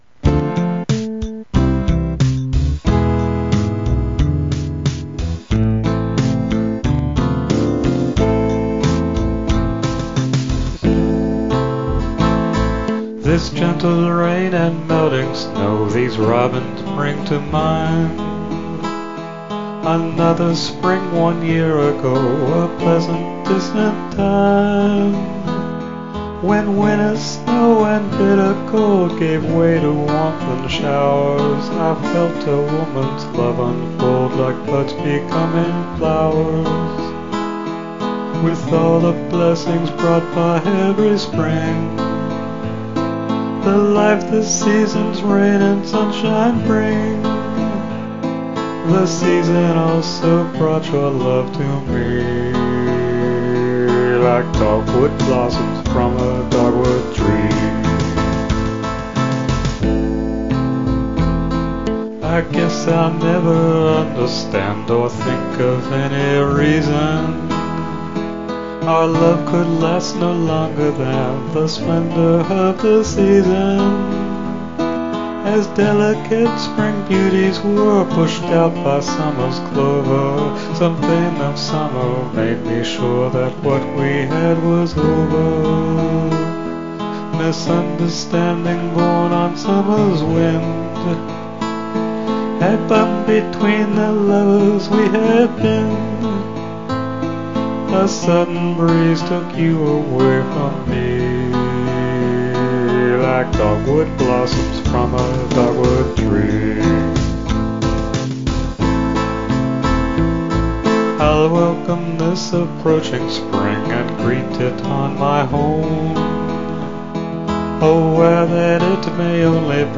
slow 4/4 filk, male or female voice